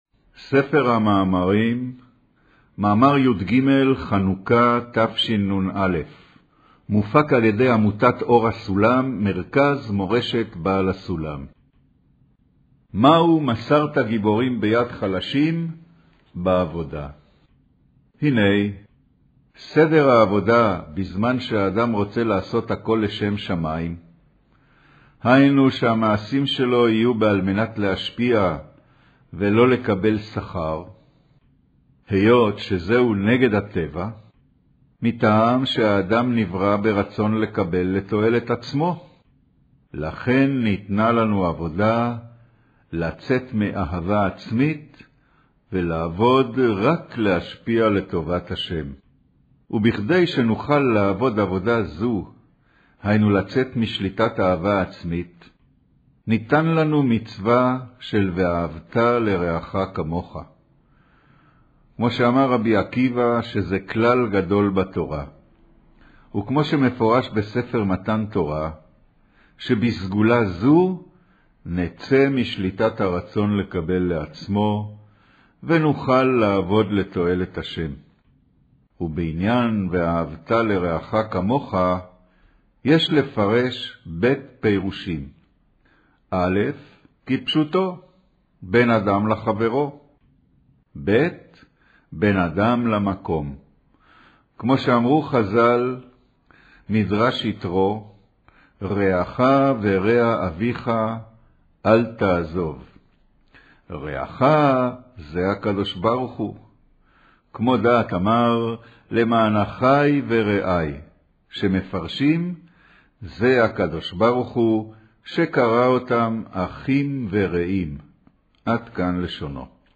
אודיו - קריינות ספר המאמרים תשנ"א